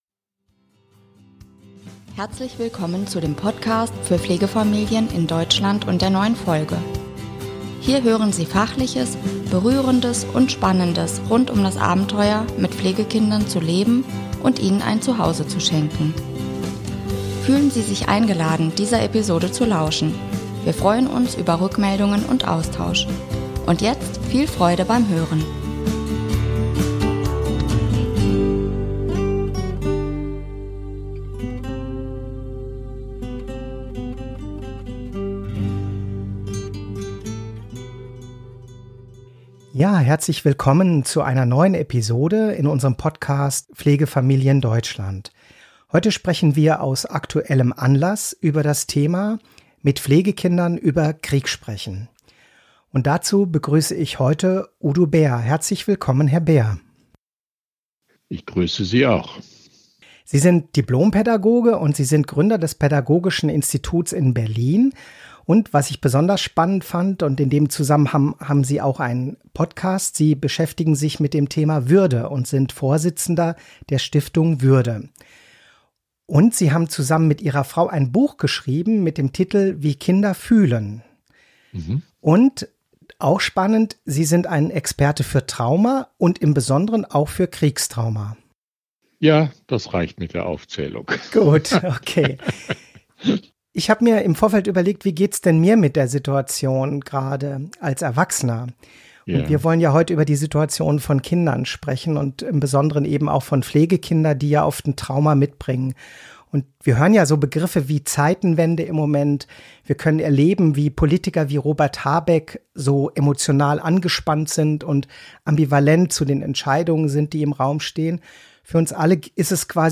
Fragen für das Interview: · Aktuell wird ja von Zeitenwende gesprochen und wir können bei Politikern z.B. bei Robert Habeck spüren, wie hoch die emotionale Anspannung ist.